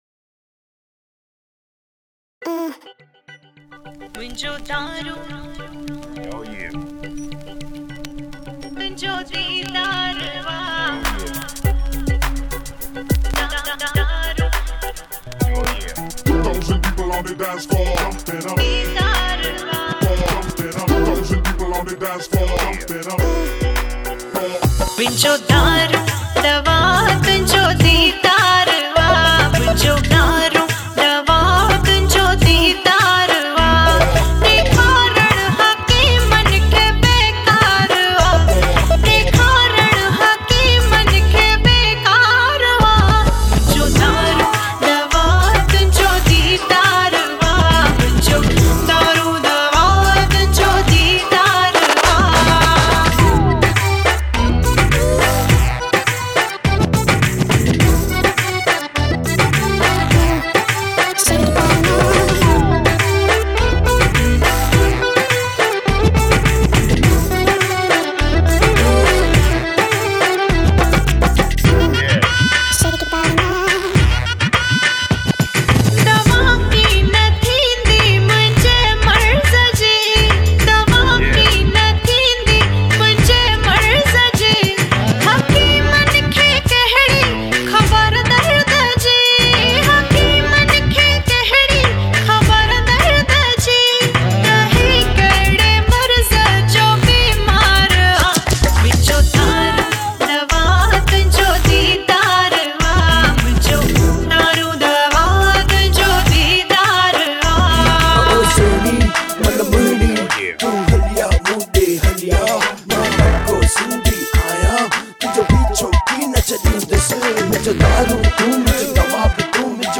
Collection Melodious Sindhi SOngs